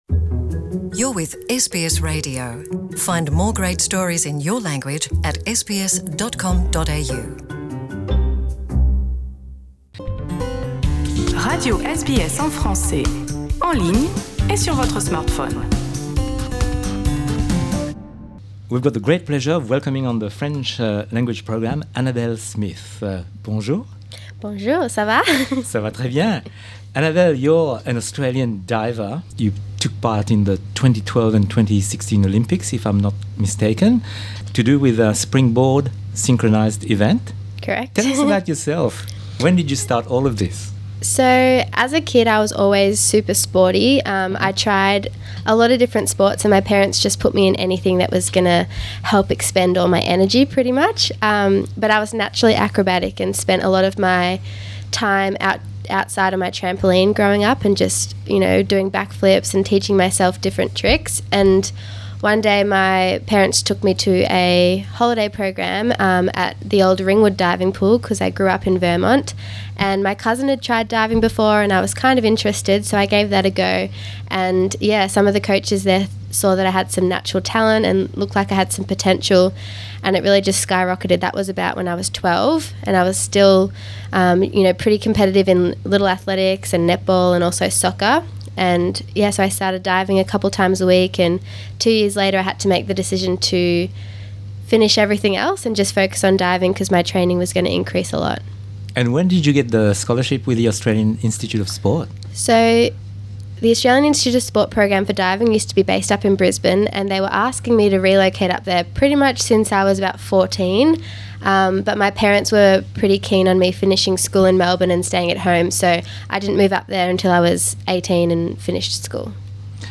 Elle se prépare pour Tokyo 2020. Cette interview a été publiée en novembre 2018.